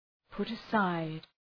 put-aside.mp3